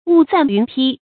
霧散云披 注音： ㄨˋ ㄙㄢˋ ㄧㄨㄣˊ ㄆㄧ 讀音讀法： 意思解釋： 喻變化之快。